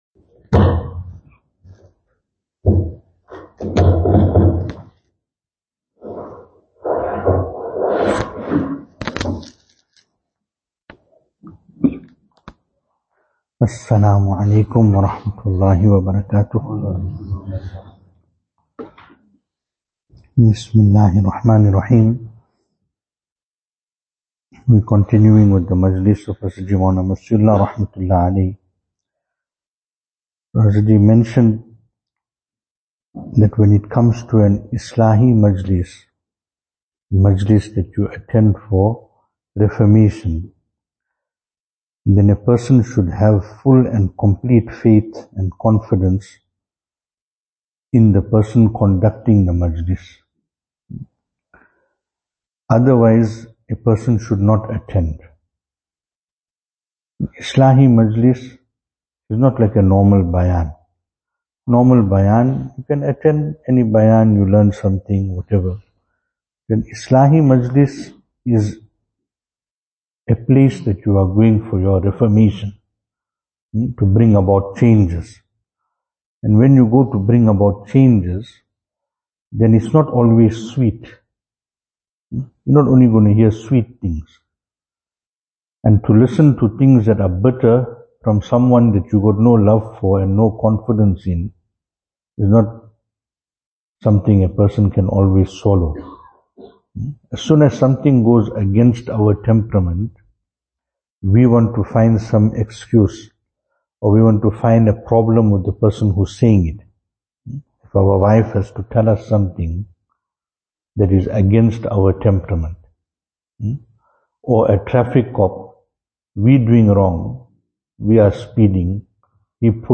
Venue: Albert Falls , Madressa Isha'atul Haq Service Type: Zikr